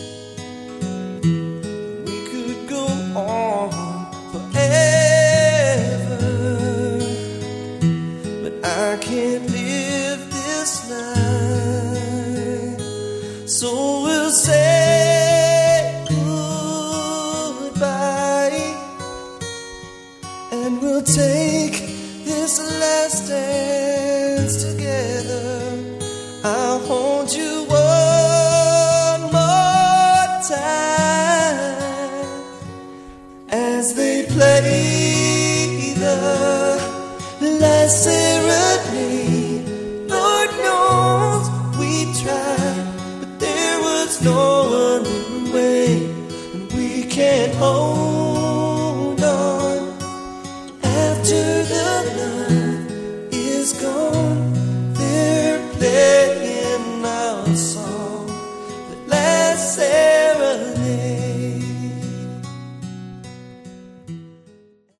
Category: Melodic Hard Rock
Vocals, Guitar, Keyboards
Bass, Vocals
Drums